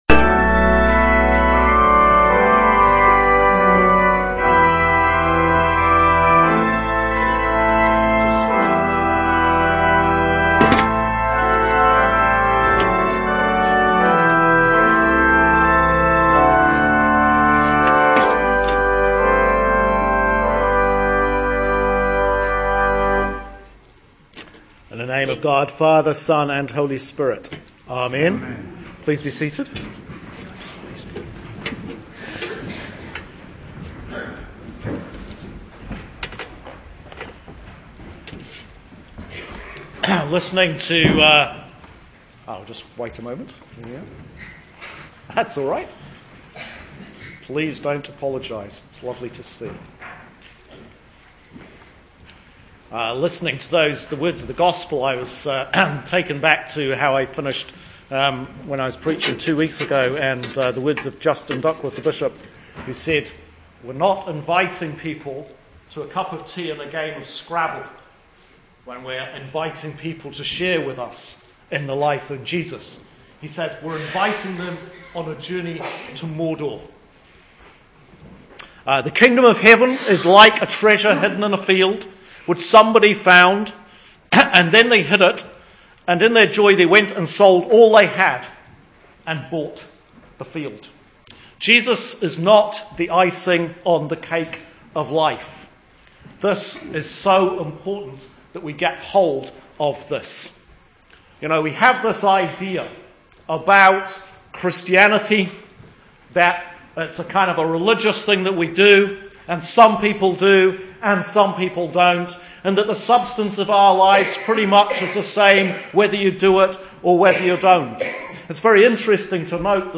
Sermon for Sunday 11th October 2015